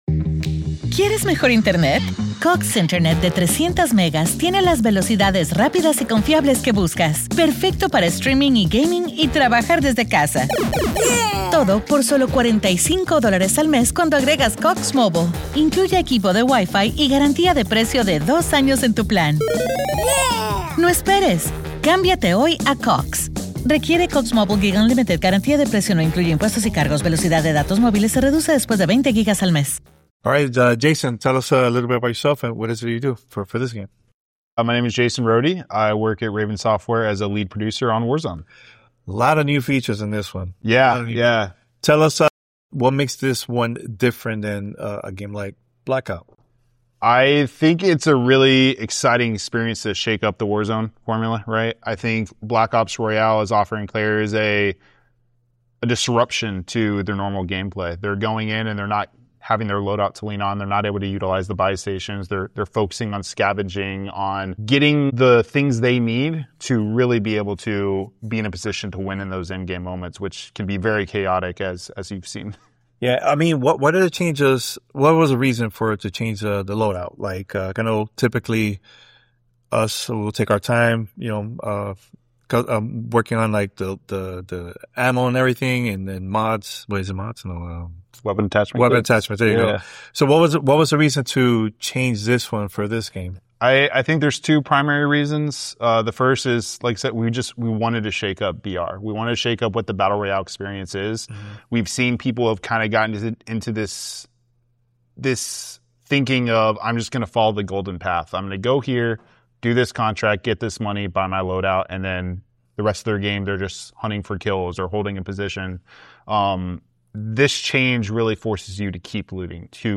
Black Ops Royal Interview with Raven Software